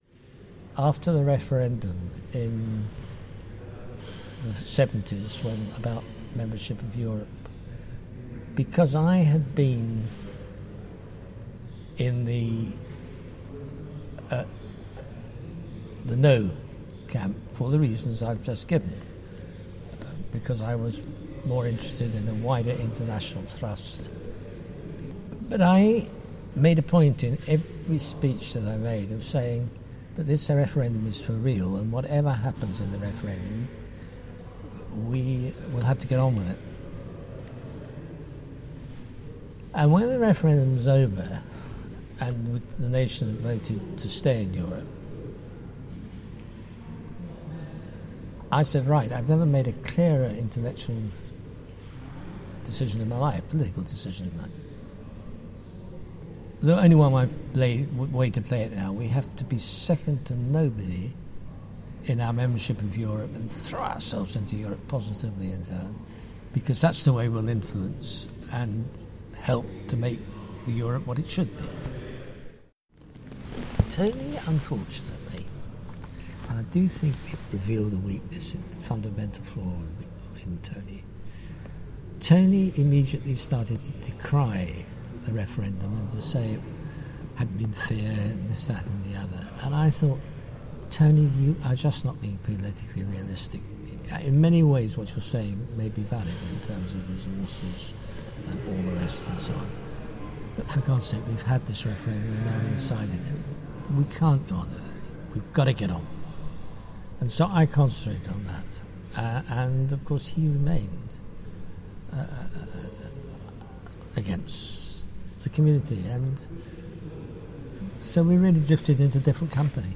The issue features prominently in our interviews with former MPs for our oral history archive.
In this clip Frank Judd remembers drifting apart from his friend and political ally Tony Benn because of the vote: